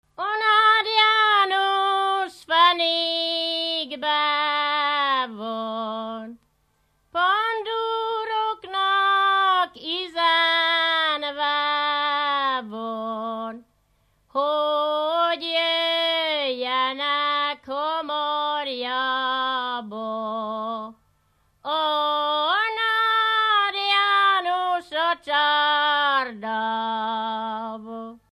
Dunántúl - Somogy vm. - Varjaskér
Műfaj: Ballada
Stílus: 6. Duda-kanász mulattató stílus
Szótagszám: 8.8.8.8